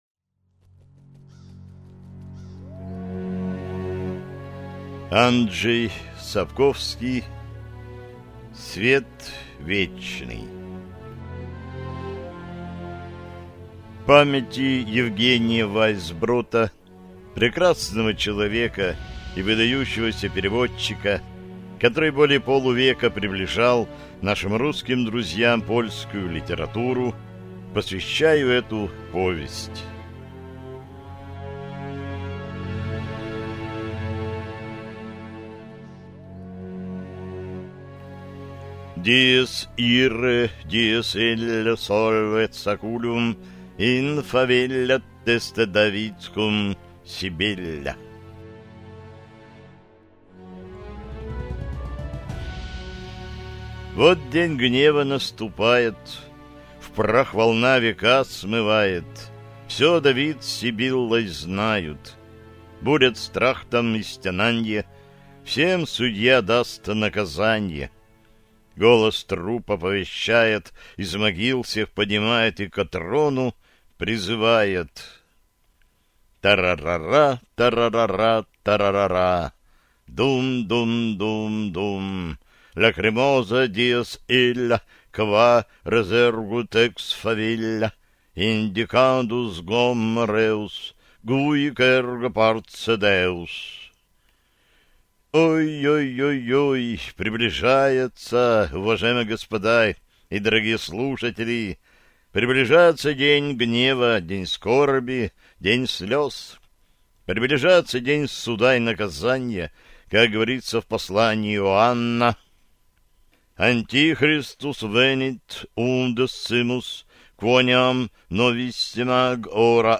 Аудиокнига Свет вечный - купить, скачать и слушать онлайн | КнигоПоиск